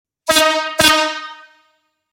Buzinas a Ar para Bicicletas Jumbinho
• 01 corneta;
• Intensidade sonora 130db;
• Acionamento através de bomba manual;